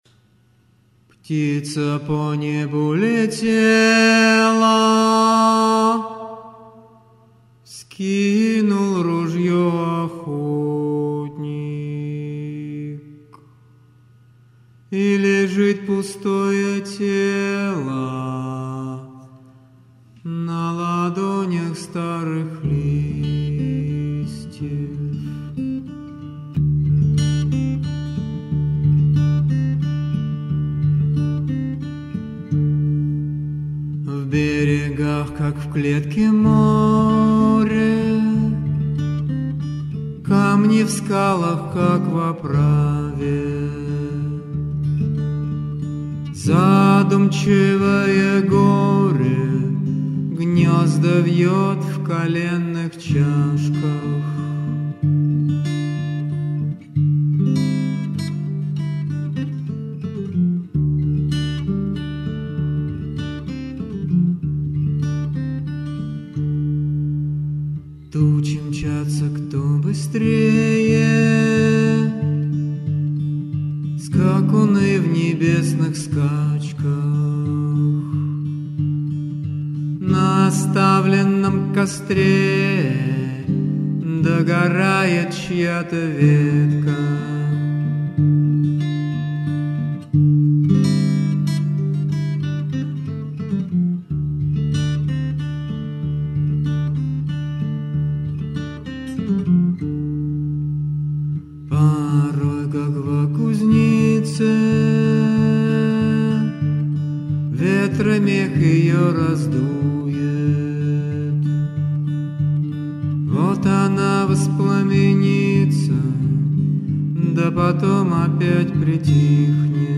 • Автор музыки: Народная мелодия Бурунди
• Жанр: Авторская песня